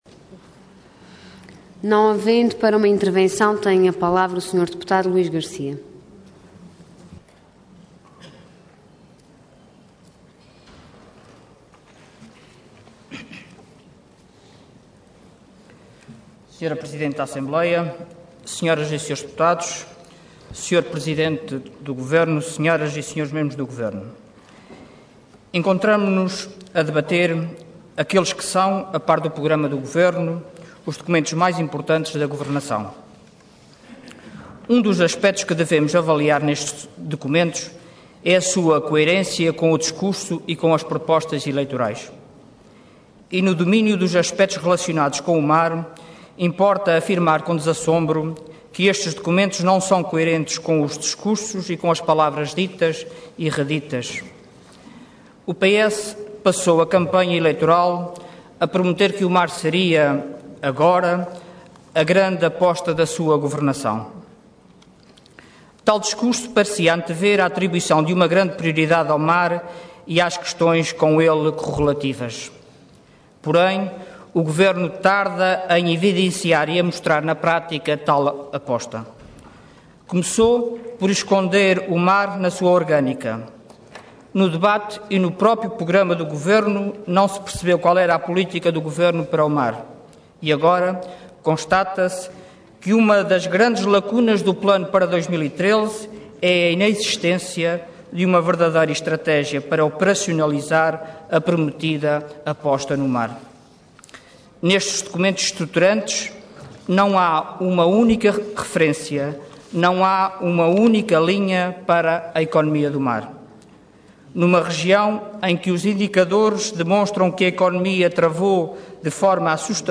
Website da Assembleia Legislativa da Região Autónoma dos Açores
Intervenção Intervenção de Tribuna Orador Luís Garcia Cargo Deputado Entidade PSD